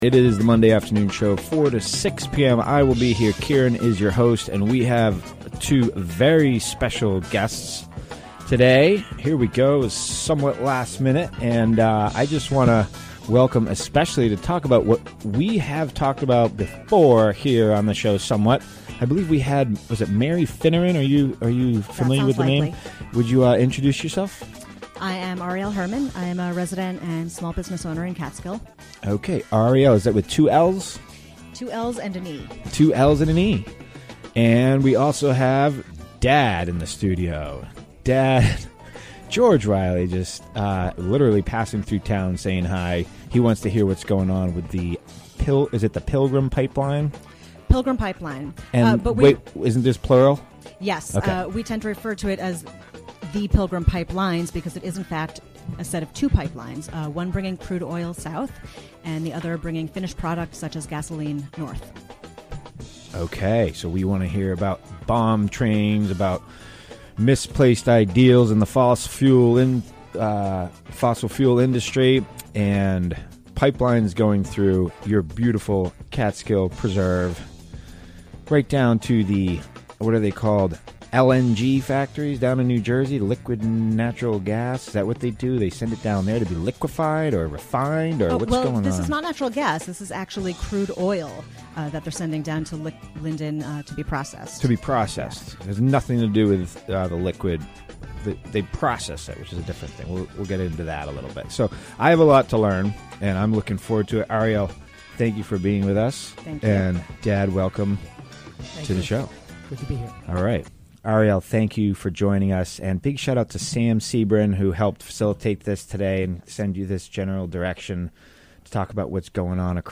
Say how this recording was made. Recorded during the WGXC Afternoon Show, Mon., Dec. 21, 2015.